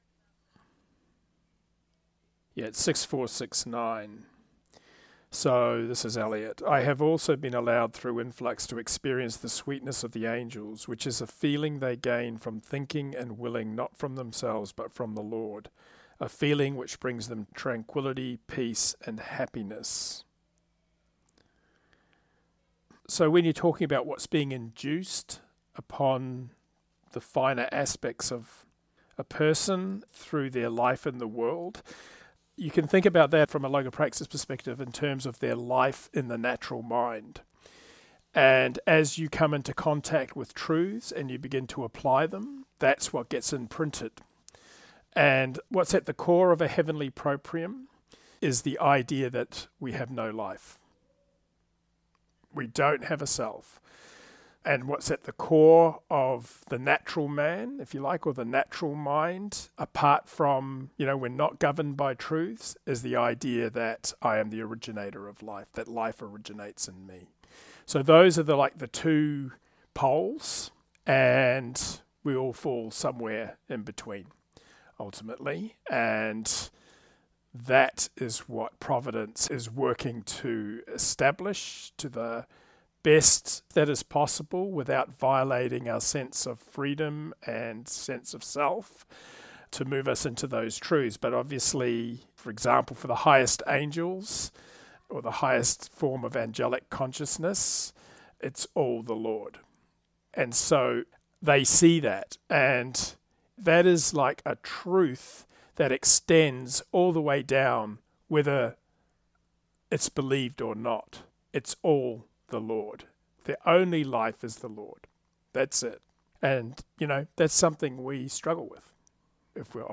Third Round posts are short audio clips taken from Round 3 comments offered in the online Logopraxis Life Group meetings. The aim is to keep the focus on understanding the Text in terms of its application to the inner life along with reinforcing any key LP principles that have been highlighted in the exchanges.